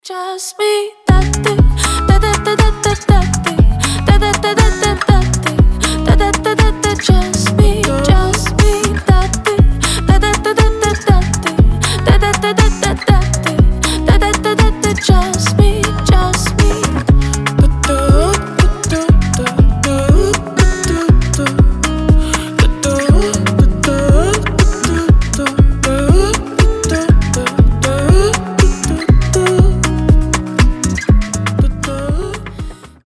легкие